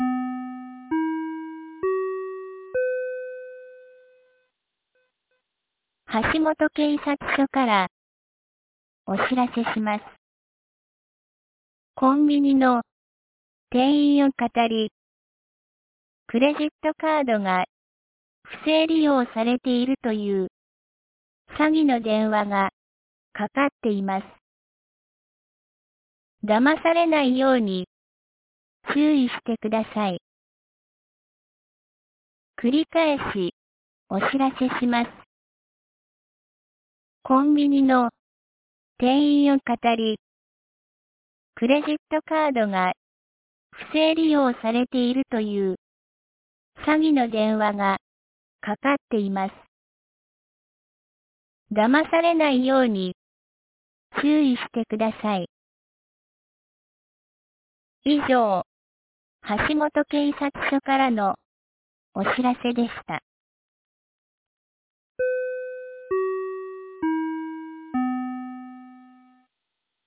九度山町放送内容 2025年01月30日12時31分 詐欺予兆電話の注意広報 | 和歌山県九度山町メール配信サービス
2025年01月30日 12時31分に、九度山町より全地区へ放送がありました。